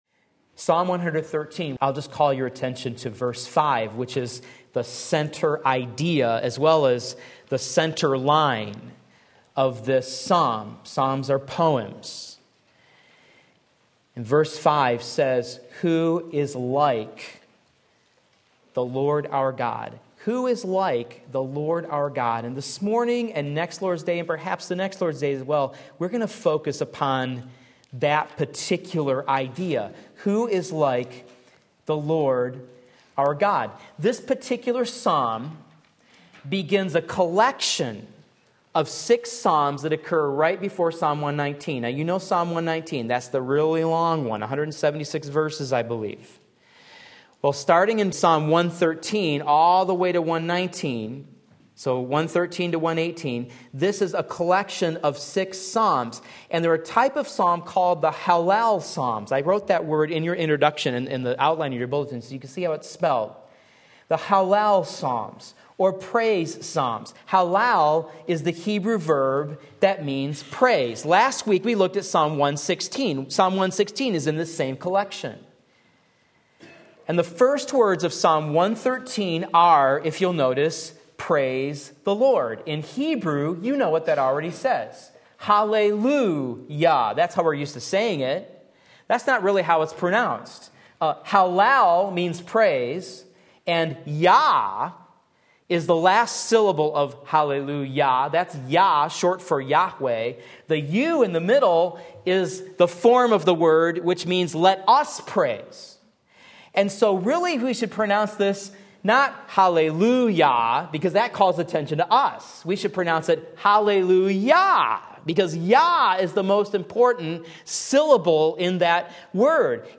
Sermon Link
- Part 1 Psalm 113 Sunday Morning Service